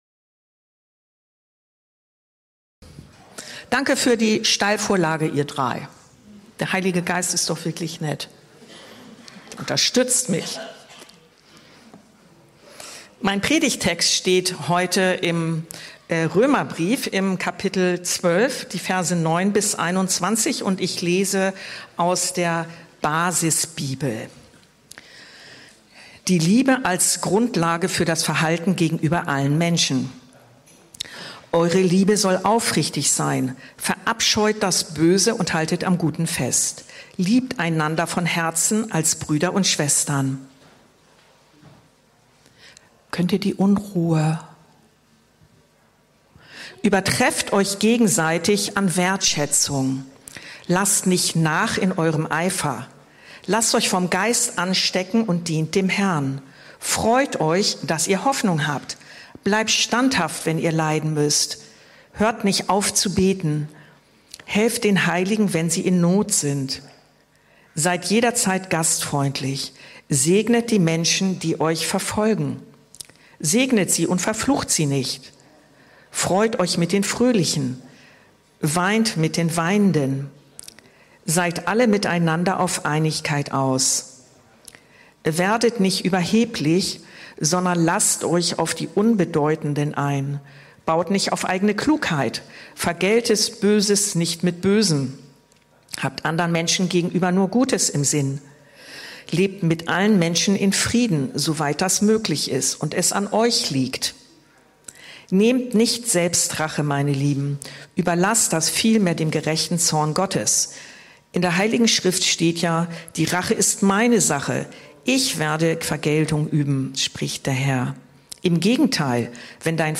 Predigten und Lehre aus der Anskar-Kirche Hamburg-Mitte